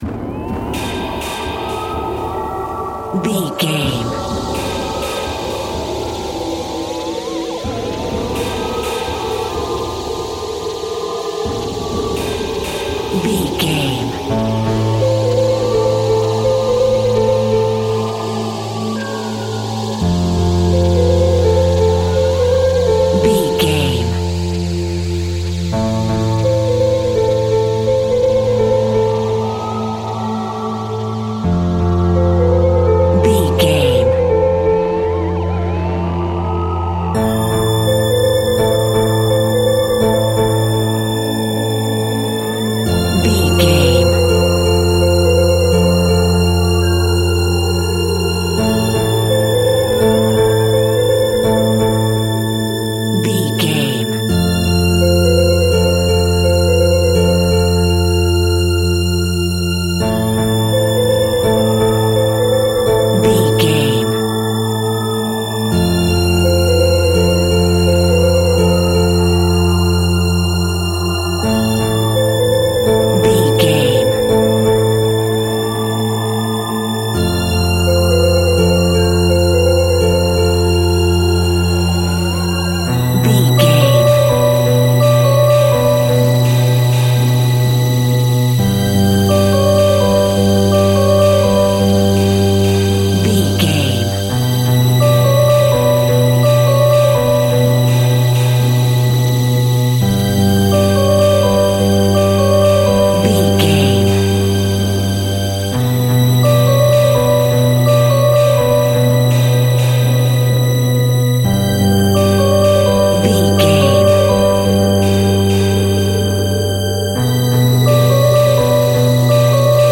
In-crescendo
Thriller
Atonal
ominous
haunting
eerie
synthesizer
piano
Horror Synths
Scary Strings